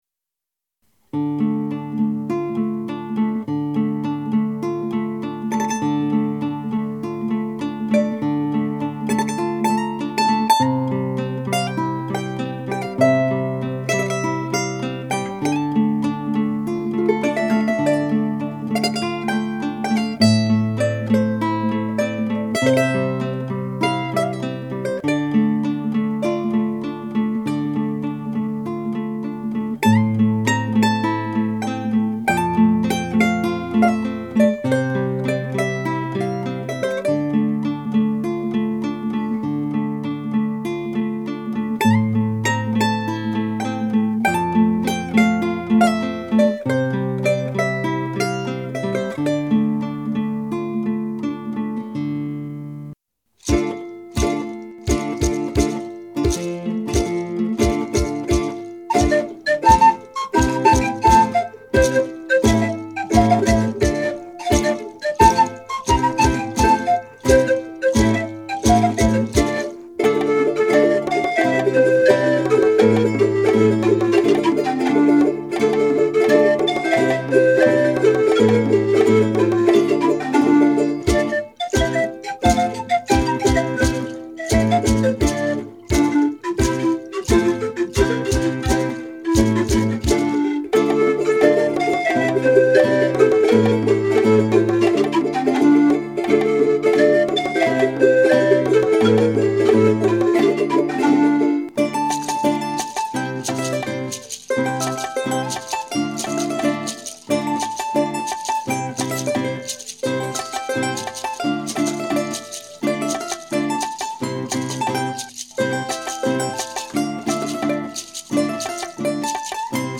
別々に録音した楽器やパソコンで作ったパーカッションをミックスして完成します。
スタジオは鍼灸院の待合室です。
チャランゴ部のメロディーが美しいです。